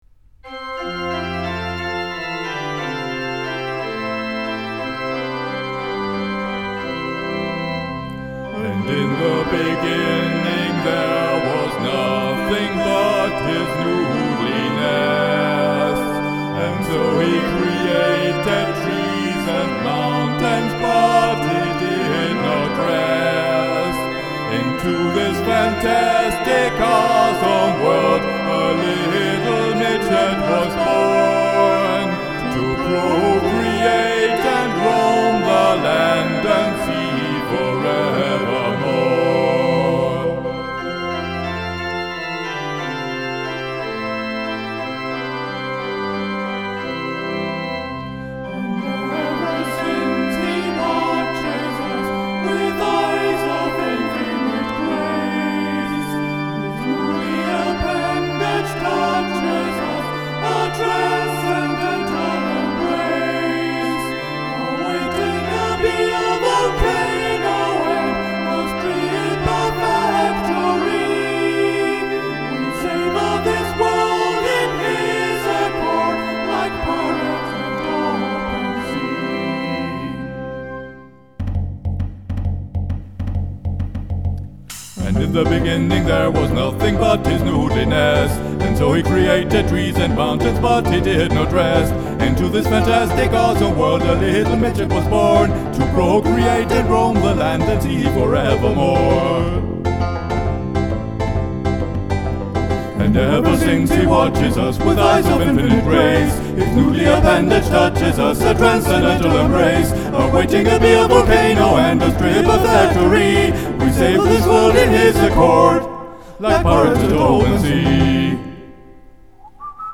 In the Beginning A Pastafarian ChriFSMas song.